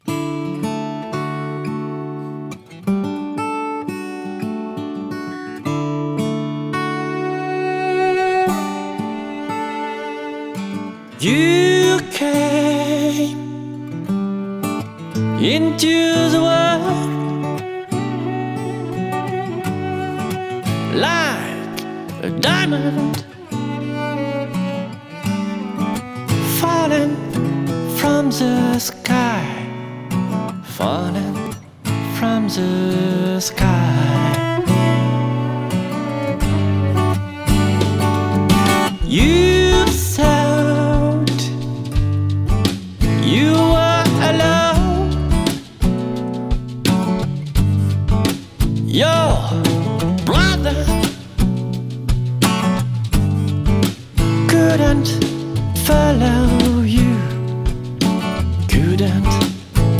Entre folk intimiste, accents soul et éclats rock
Guitariste passionné